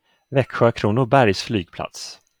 Växjö-Kronobergs flygplats, [ˈvɛkːɧøː ˈkrûːnʊˌbærj ˈflyːɡplats]